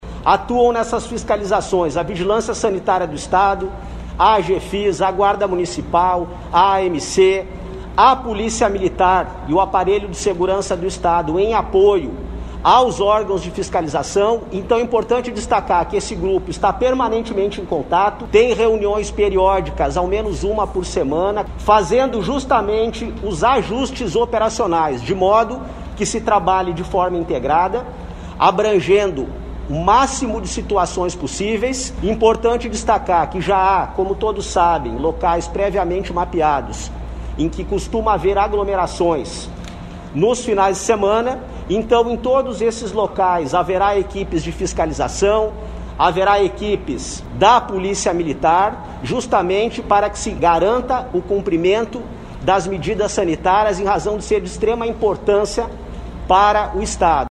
Em todos esses locais, haverá equipes de fiscalização e da Polícia Militar, garantindo o cumprimento das medidas sanitárias em razão de ser de extrema importância para o Estado”, ressaltou o secretário da Secretaria da Segurança Pública e Defesa Social (SSPDS), Sandro Caron, durante coletiva de imprensa.
O secretário da Segurança Pública, Sandro Caron, destacou que as forças de segurança do Ceará vão se integrar aos órgãos do município de Fortaleza para fazer cumprir a lei e garantir que não haja aglomerações.